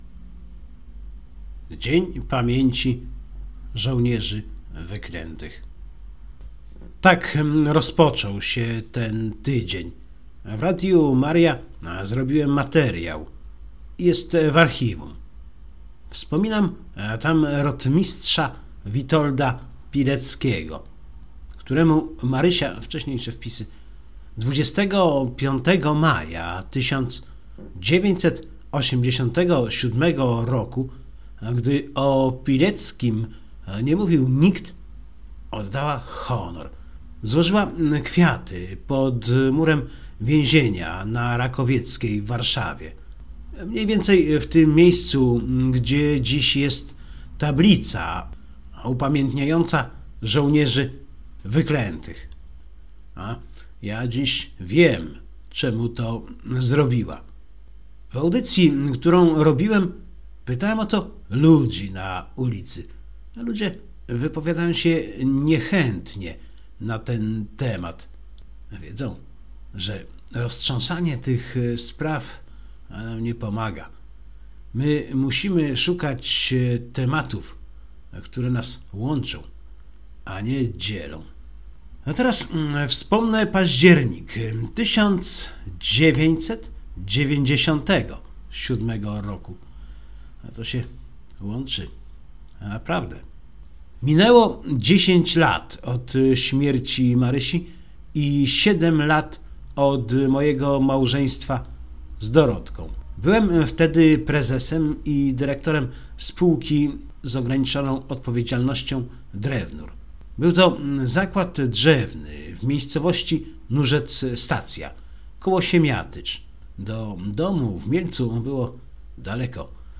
Ja dziś wiem czemu to zrobiła – w audycji, którą robiłem pytałem o to ludzi na ulicy. Ludzie wypowiadają się niechętnie na ten temat.